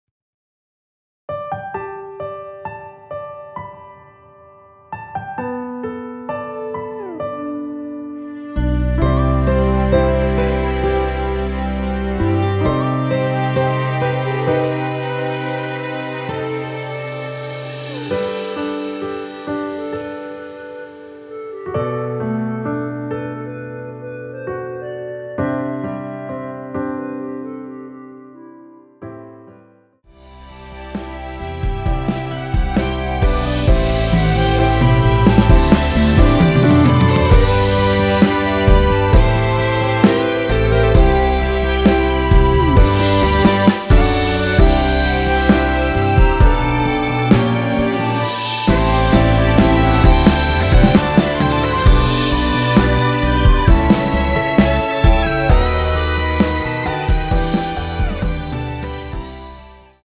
원키에서(+4)올린 멜로디 포함된 MR입니다.(미리듣기 확인)
멜로디 MR이라고 합니다.
앞부분30초, 뒷부분30초씩 편집해서 올려 드리고 있습니다.
중간에 음이 끈어지고 다시 나오는 이유는